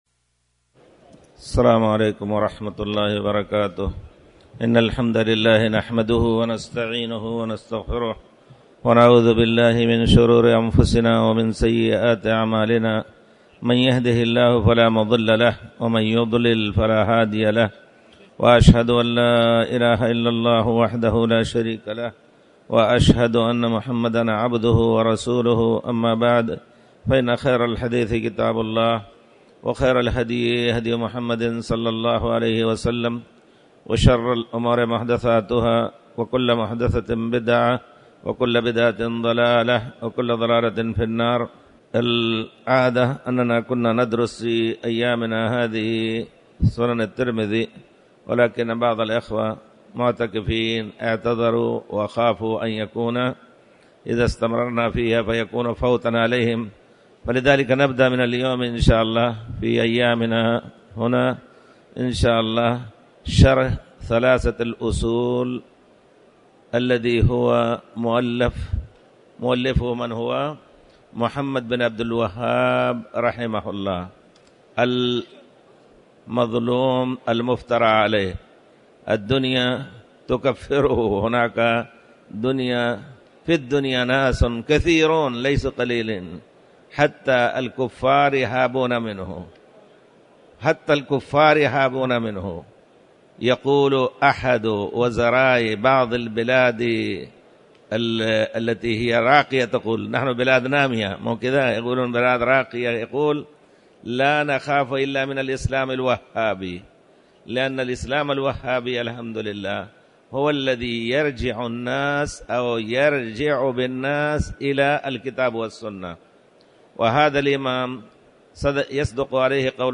تاريخ النشر ٢١ رمضان ١٤٣٩ هـ المكان: المسجد الحرام الشيخ